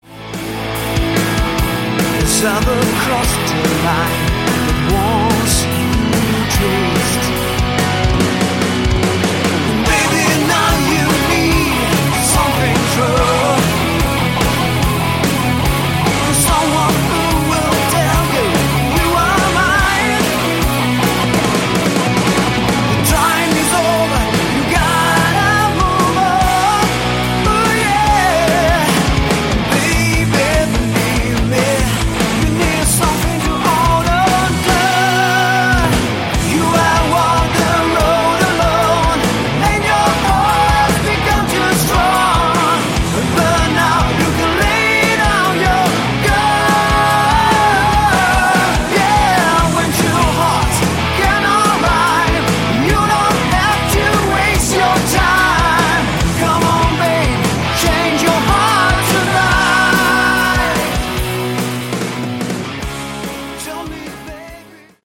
Category: Melodic Hard Rock
lead and backing vocals
rhythm guitars, lead and acoustic guitars
lead guitars, guitar solos
bass
drums